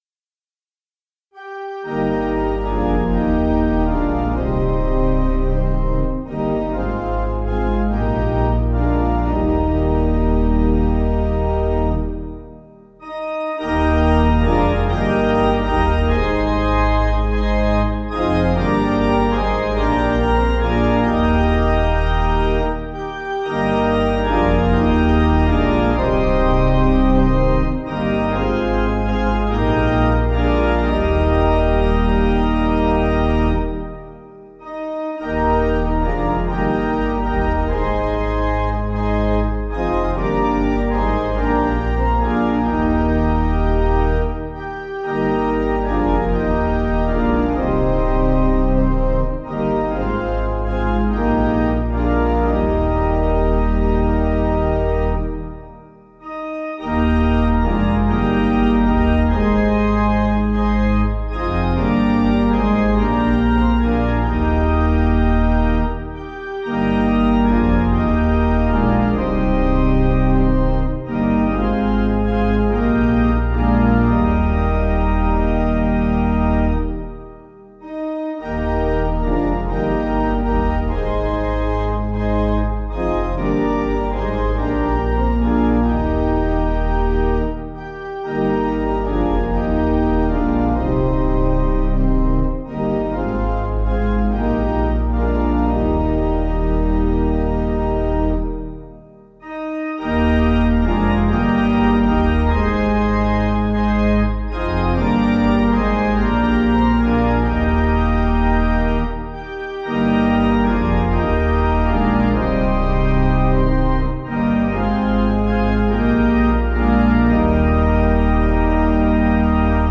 Meter: 7.6.7.6
Key: E♭ Major
German Traditional Melody